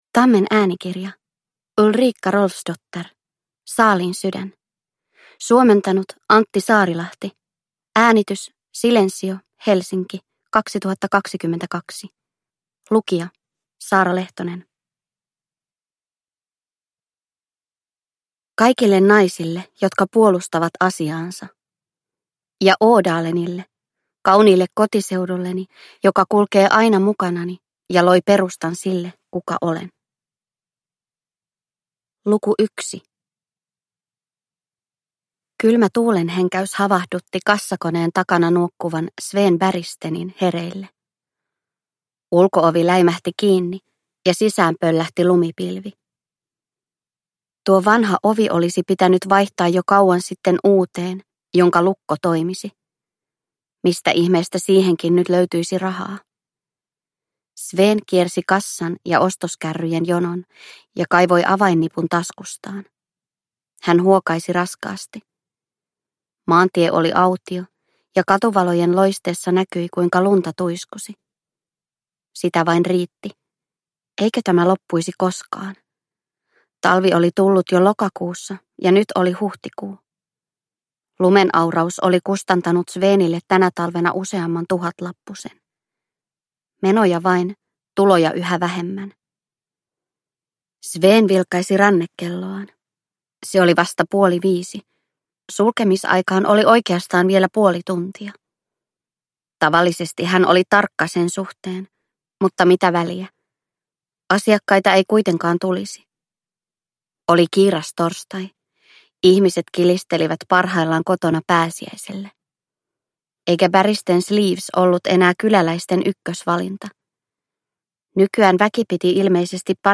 Saaliin sydän – Ljudbok – Laddas ner